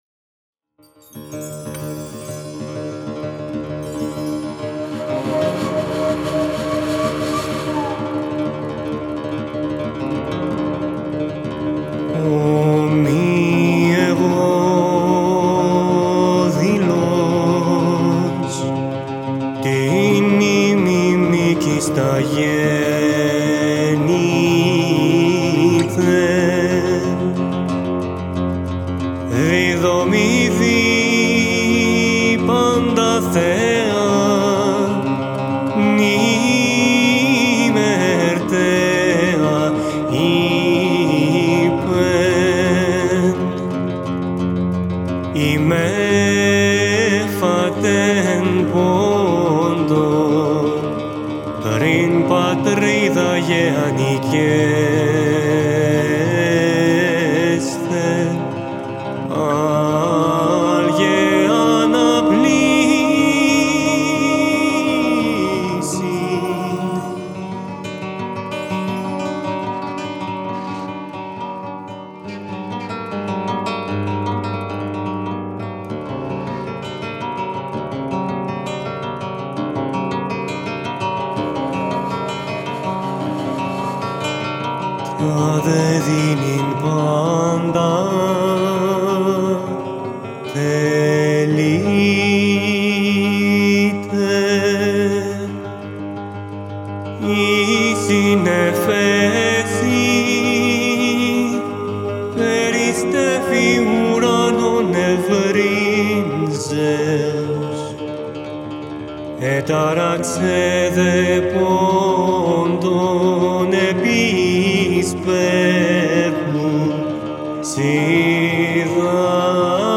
An Approach to the Original Singing of Homeric epics